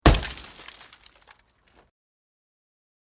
ベシャー（48KB）
マルチメディアカード記録済み効果音12種類